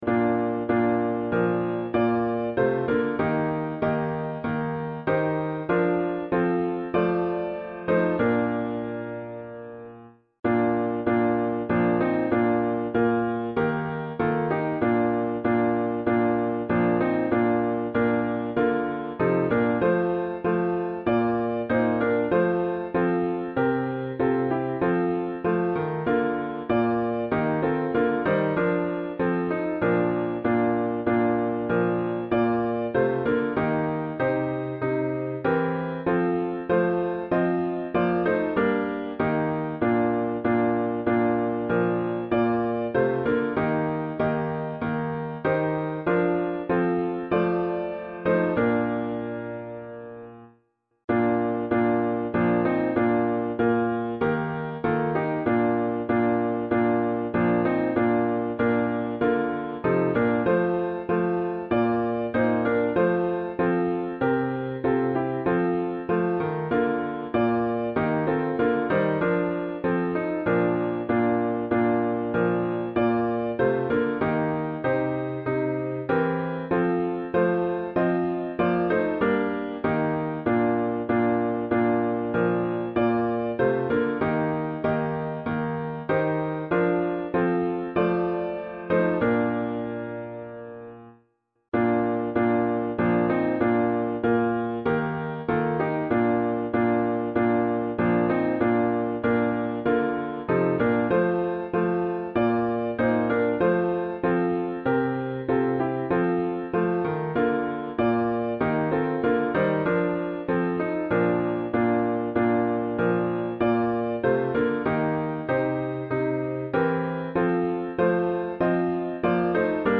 伴奏
示范